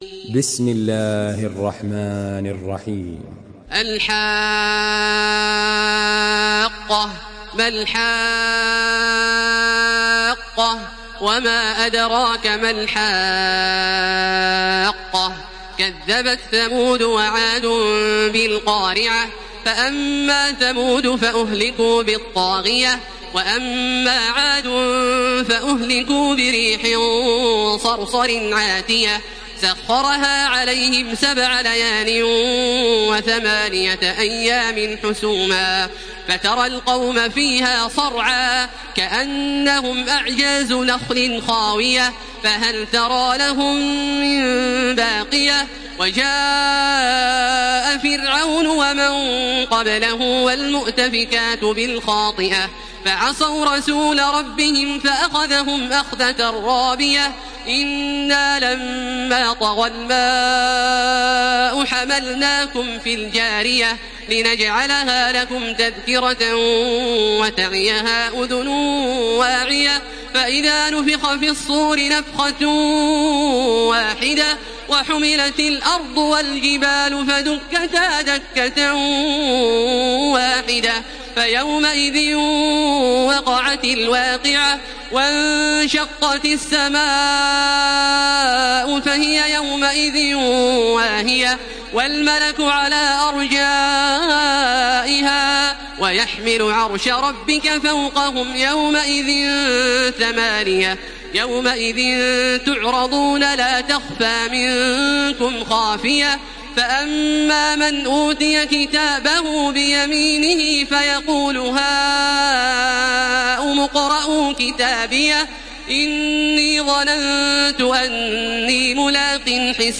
Surah আল-হাক্কাহ্ MP3 by Makkah Taraweeh 1433 in Hafs An Asim narration.
Murattal